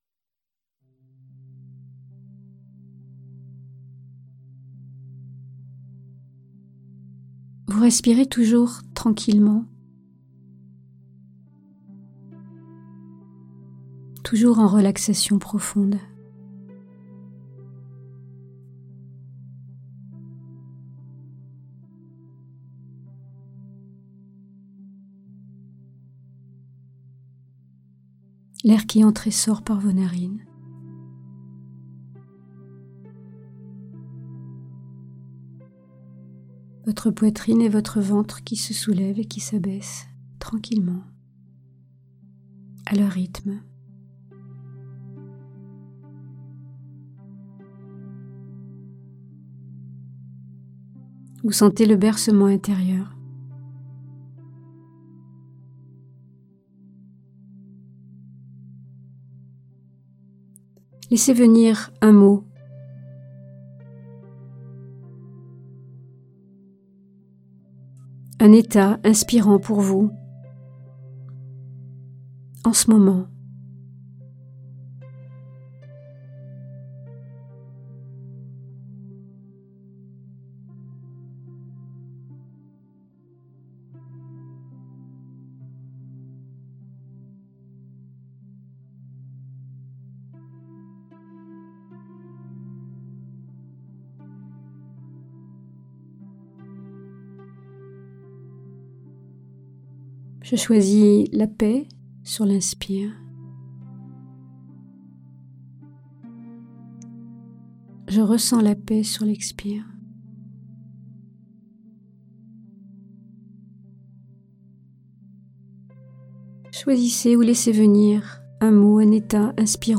Méditations guidées pour trouver le sommeil